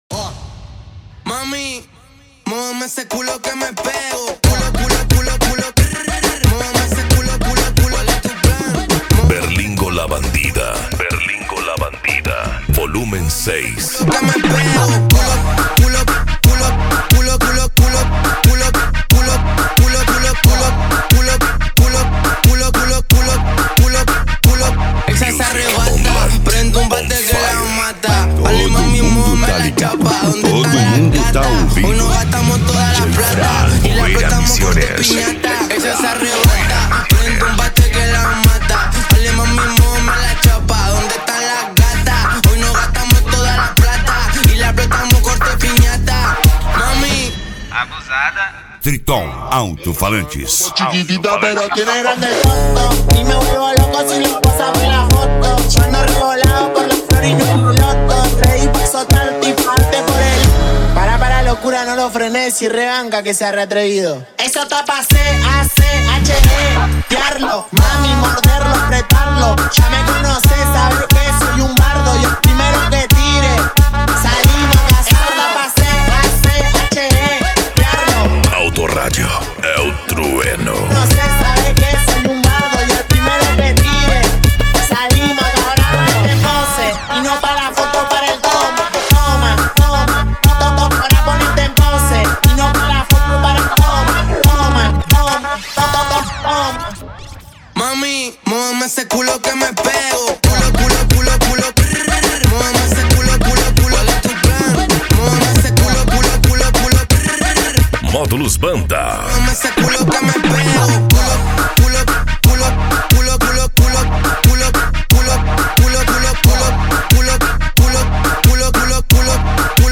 Funk
Mega Funk
Remix